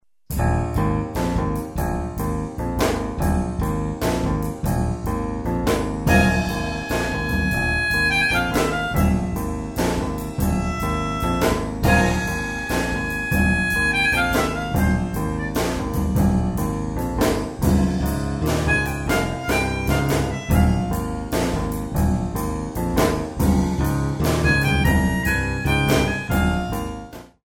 7/8  mm=144